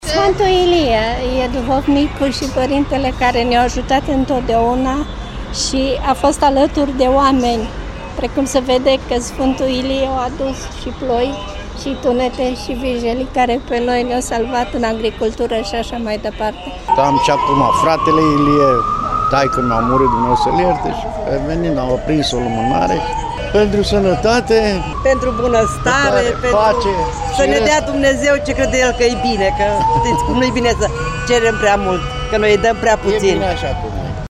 Sute de credincioşi au luat parte la slujba oficiată luni, de Sfântul Ilie, la Catedrala Mitropolitană din Timişoara.
voxuri-sf-ilie.mp3